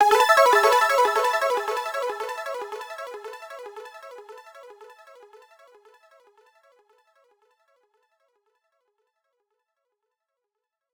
Echoes_Amaj.wav